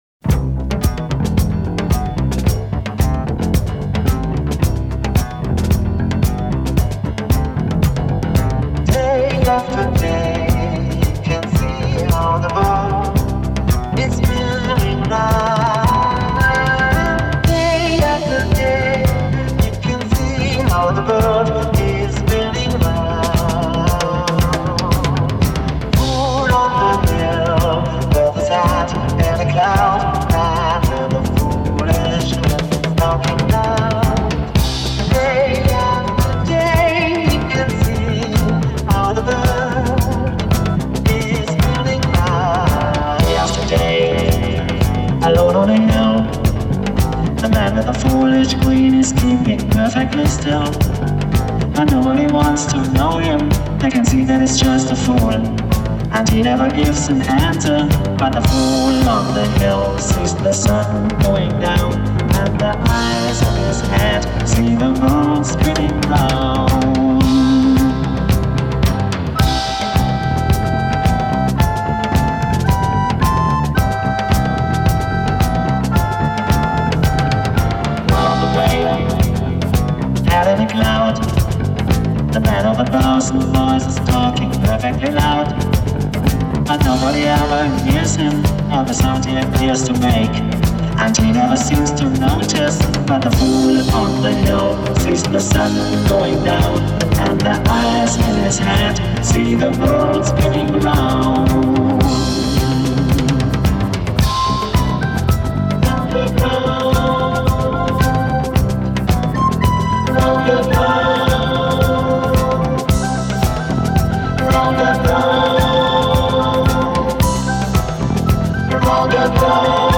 Ищу диско-версии данной песни с вокалом :)
Из диско, пожалуй, самая известная от этого немца.